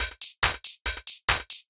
140_HH+clap_1.wav